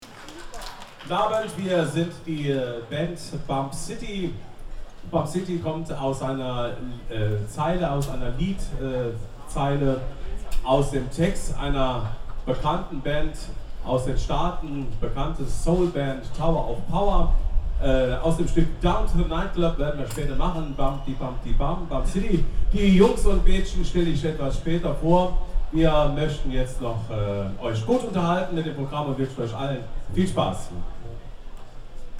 02 - Ansage.mp3